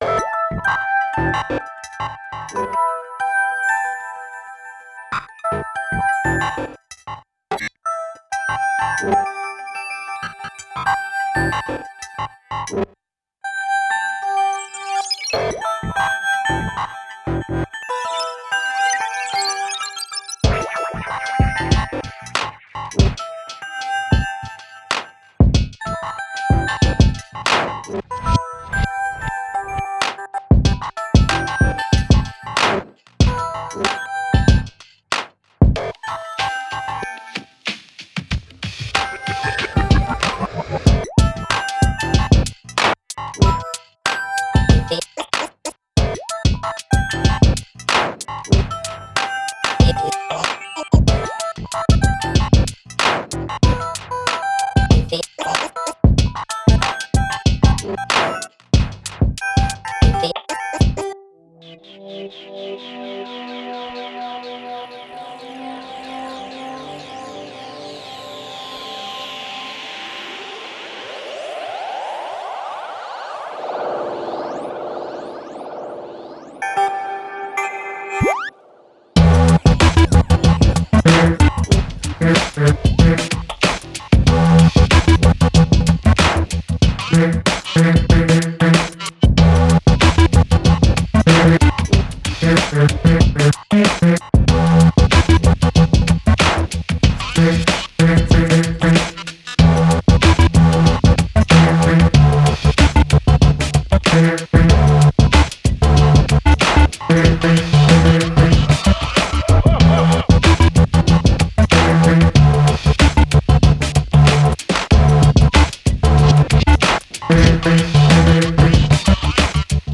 Genre: IDM, Glitch, Downtempo.